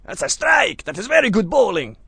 b_thatsaStrike.wav